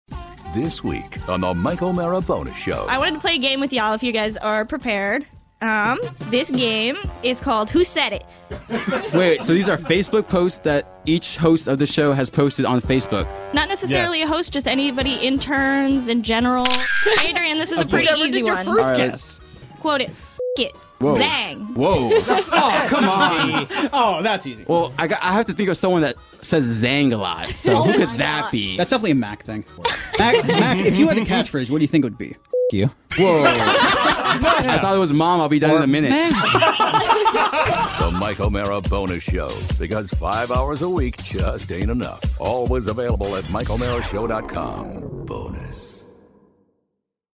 It’s an ALL intern show!